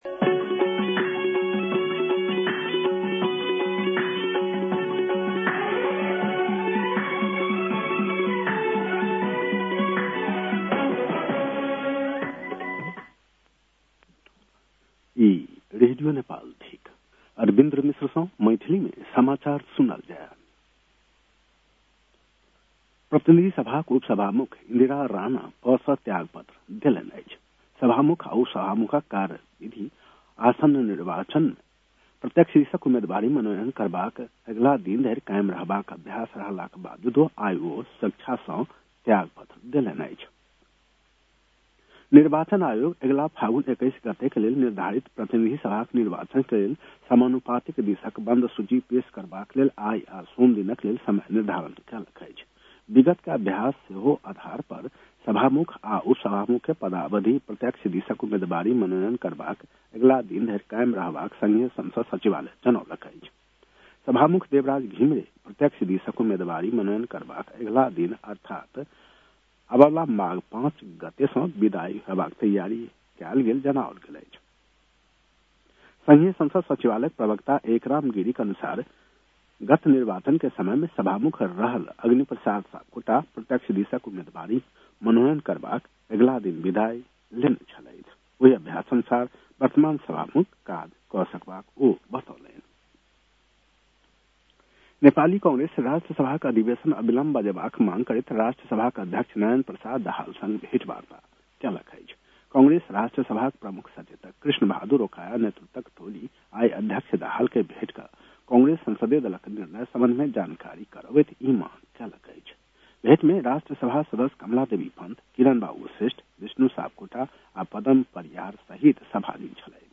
मैथिली भाषामा समाचार : १३ पुष , २०८२